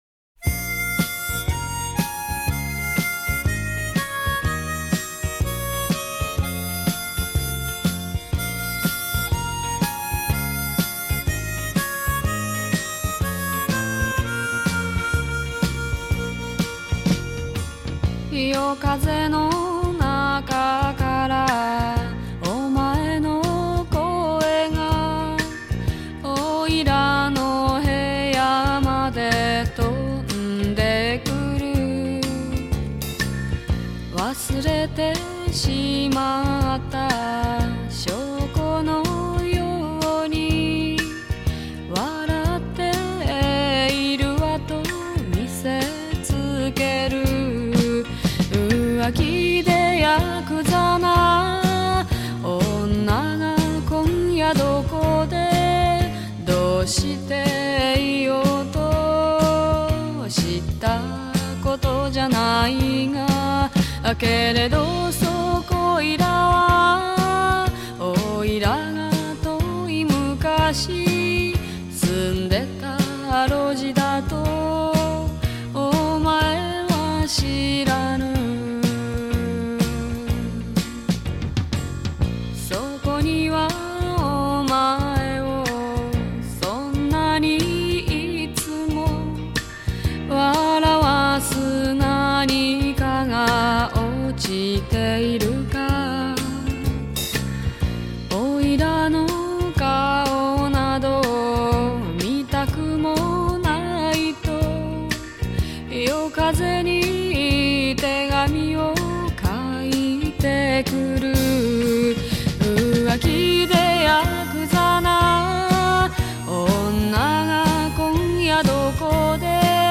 口琴跟着传统歌谣的节拍吹起